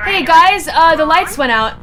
Worms speechbanks
Comeonthen.wav